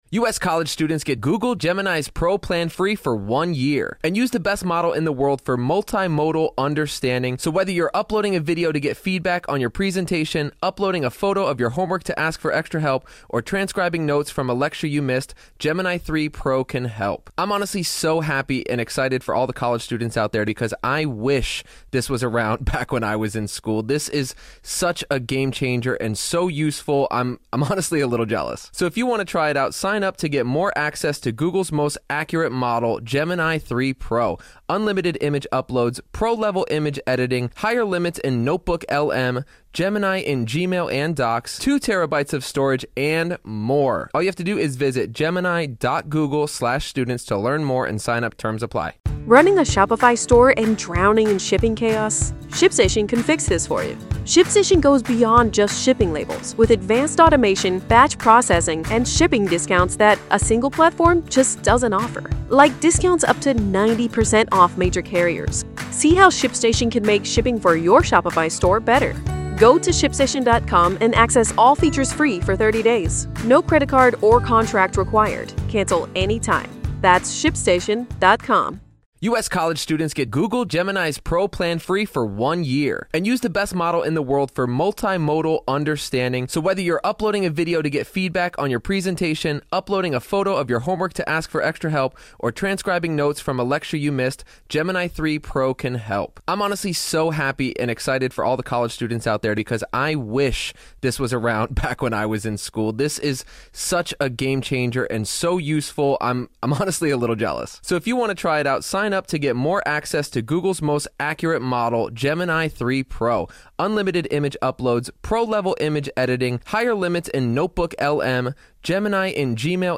LIVE COURTROOM COVERAGE — NO COMMENTARY
There is no editorializing, no added narration, and no commentary — just the court, the attorneys, the witnesses, and the judge.